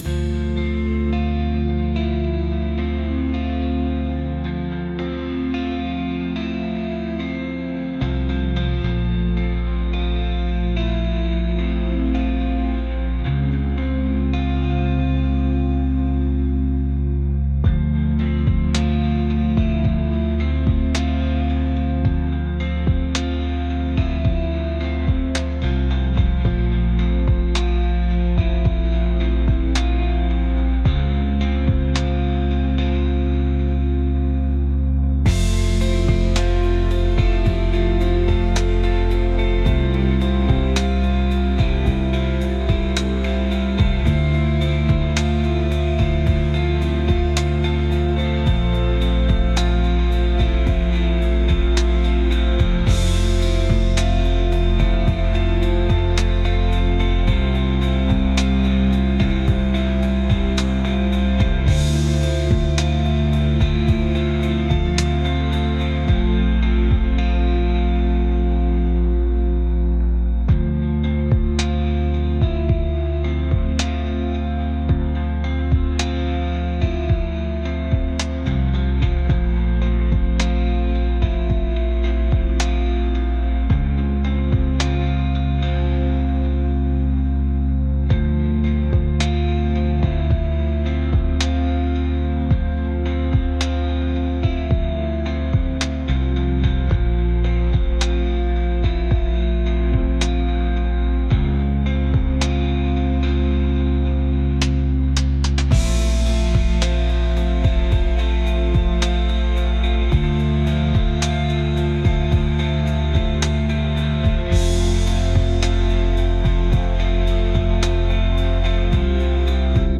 ambient | indie | cinematic